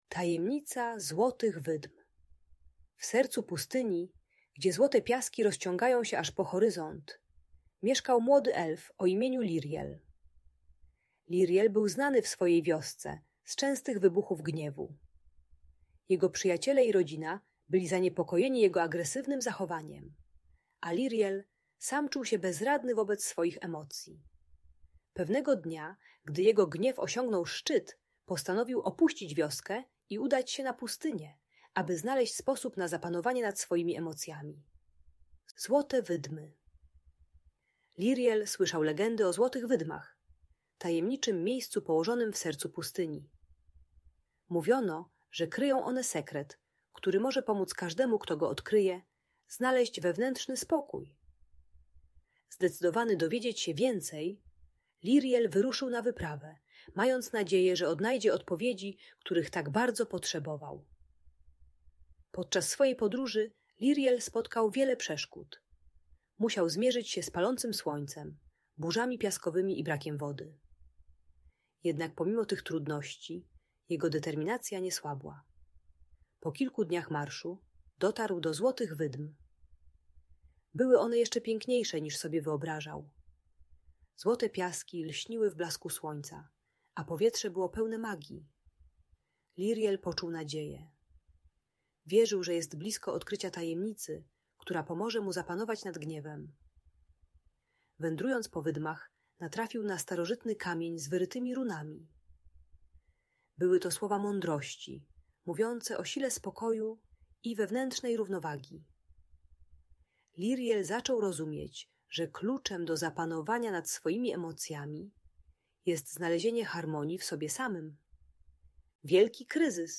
Tajemnica Złotych Wydm - Bunt i wybuchy złości | Audiobajka